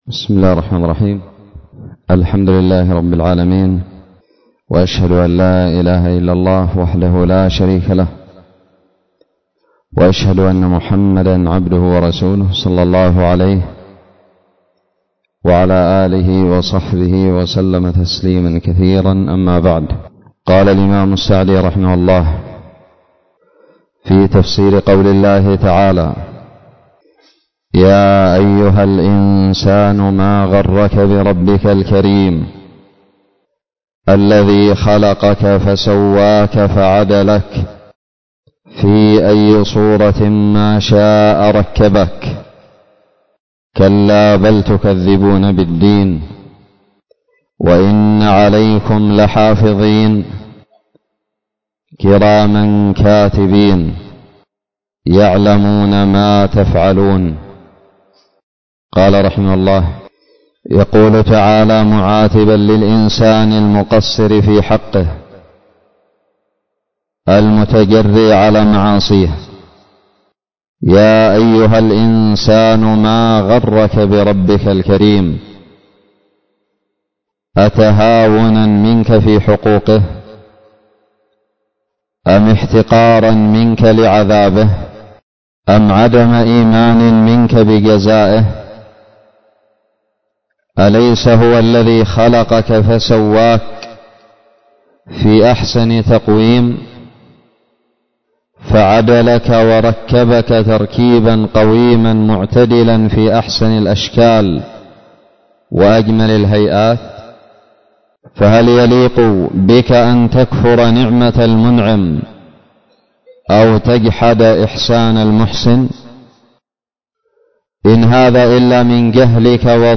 الدرس الثاني من تفسير سورة الإنفطار
ألقيت بدار الحديث السلفية للعلوم الشرعية بالضالع